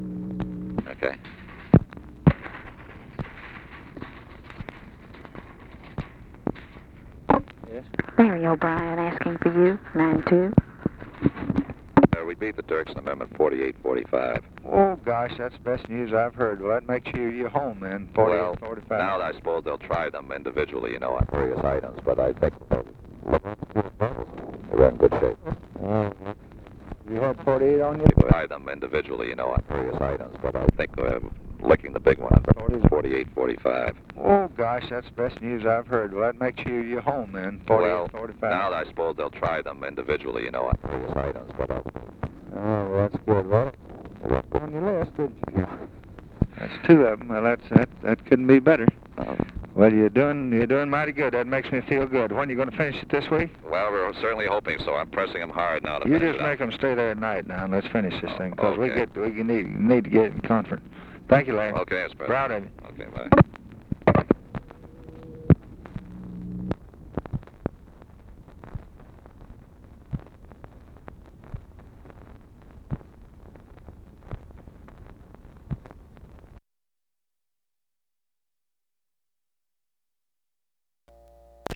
Conversation with LARRY O'BRIEN, February 5, 1964
Secret White House Tapes